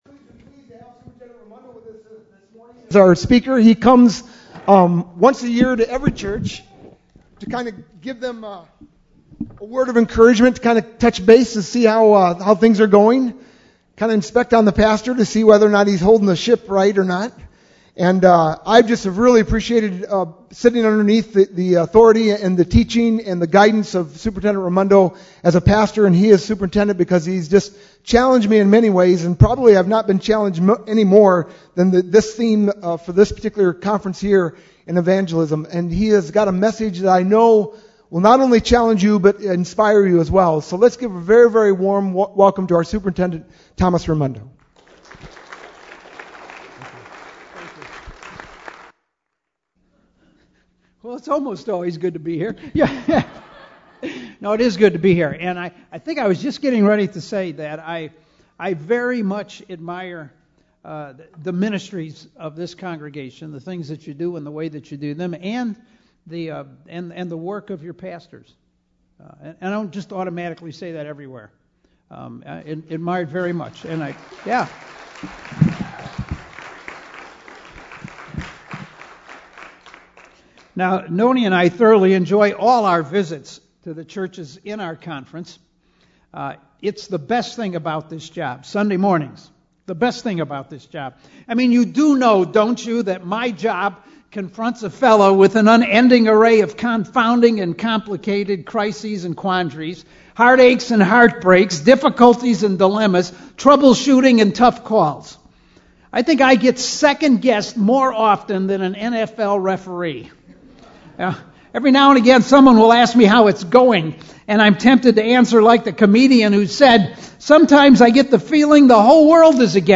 sermon-11-6-11.mp3